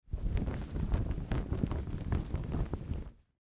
Звуки флага
Шум флага, трепещущего на ветру